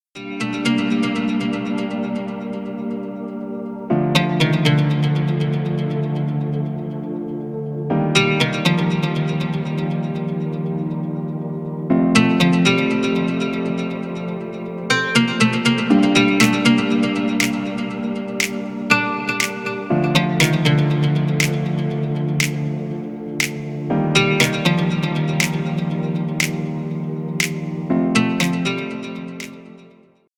Танцевальные
без слов # восточные